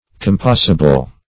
Search Result for " compassable" : The Collaborative International Dictionary of English v.0.48: Compassable \Com"pass*a*ble\, a. Capable of being compassed or accomplished.